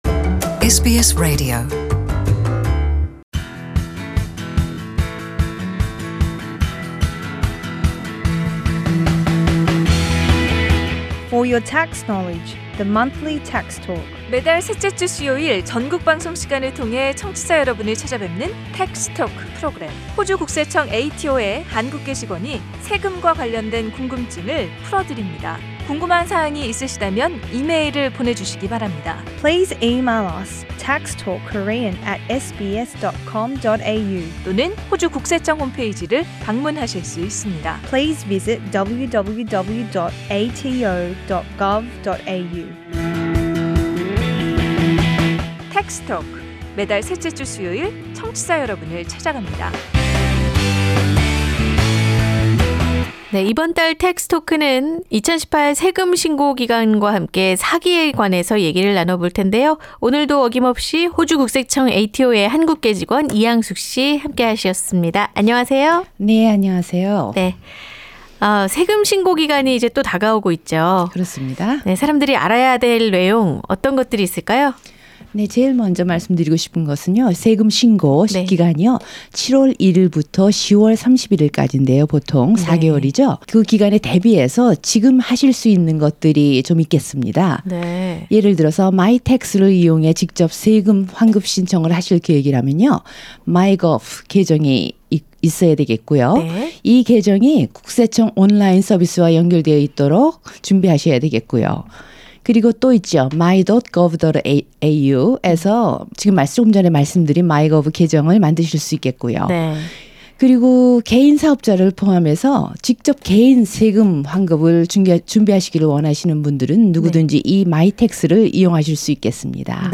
The following community information is brought to you by the Australian Taxation Office. Interview